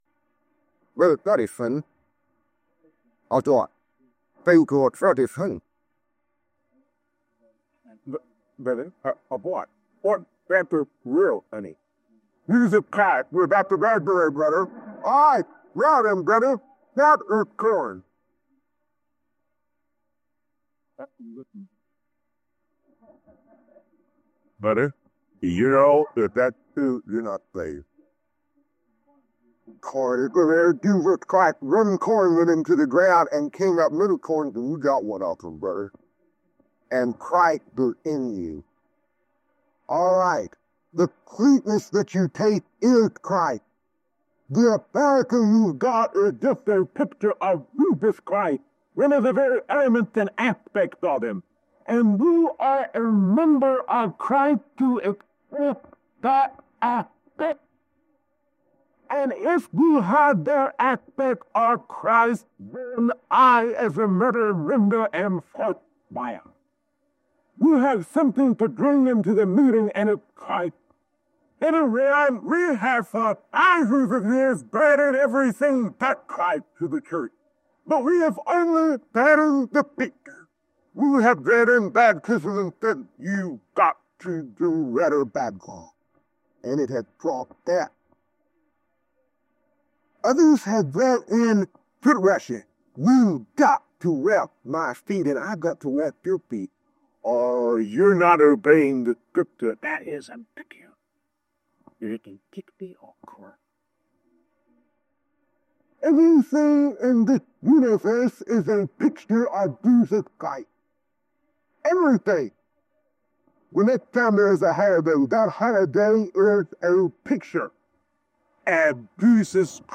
In this message, he explores the profound truth that every shadow—from the Sabbath to the ancient sacrifices—finds its ultimate fulfillment in the person of Jesus.